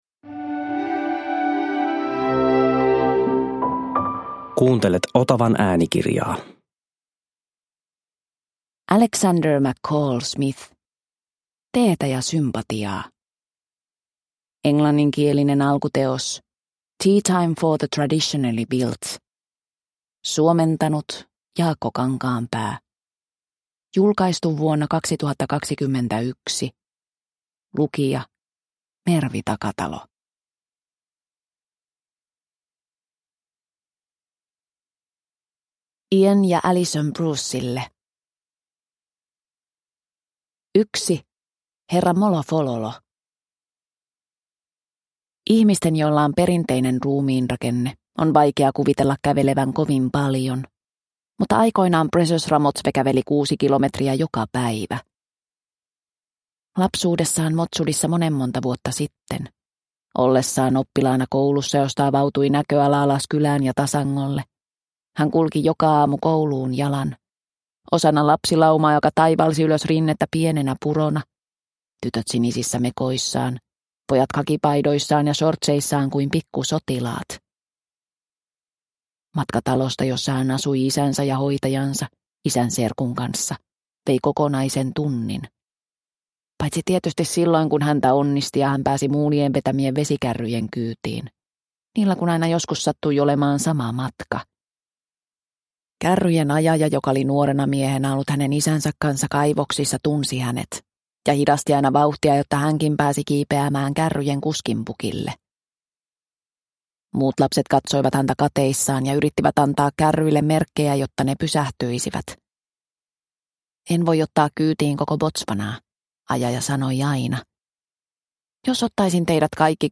Teetä ja sympatiaa – Ljudbok – Laddas ner